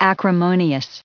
added pronounciation and merriam webster audio
135_acrimonious.ogg